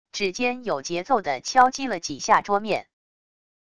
指尖有节奏的敲击了几下桌面wav音频